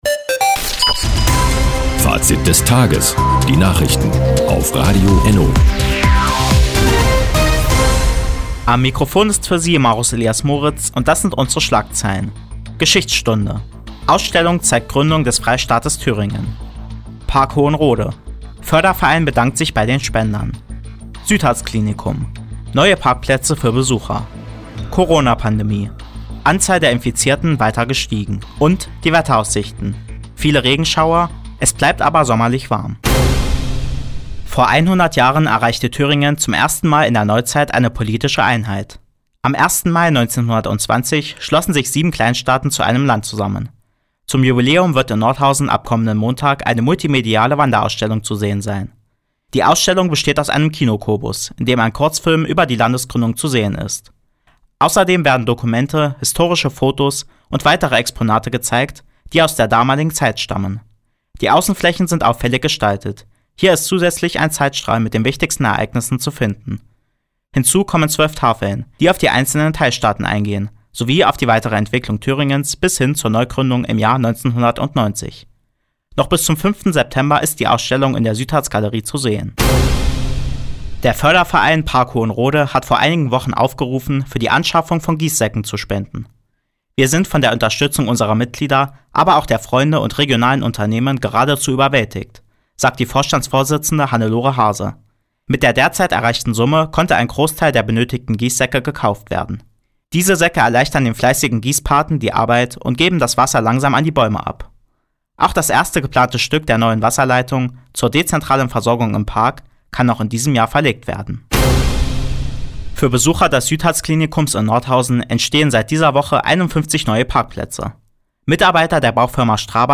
Die tägliche Nachrichtensendung ist jetzt hier zu hören.